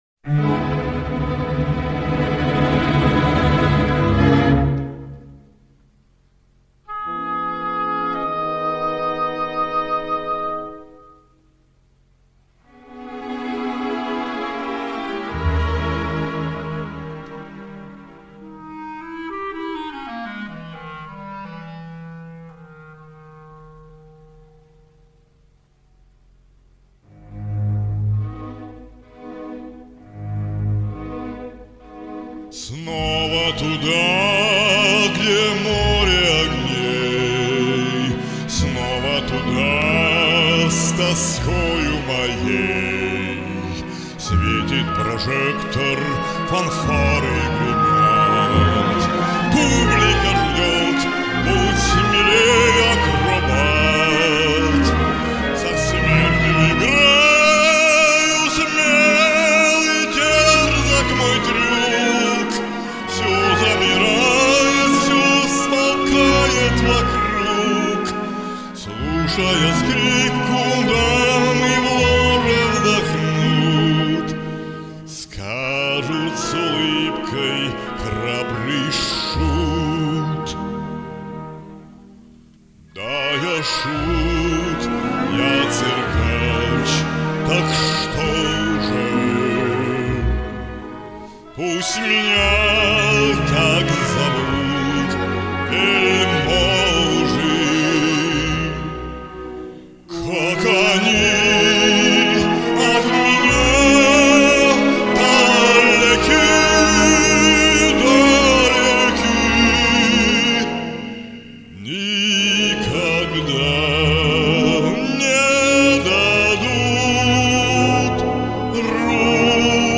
У соперников разные диапазоны и тембры голосов.
бас